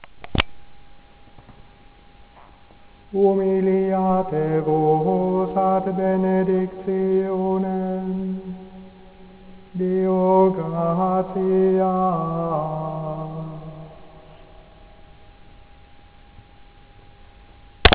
Recordings Cistercian chant